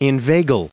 1664_inveigle.ogg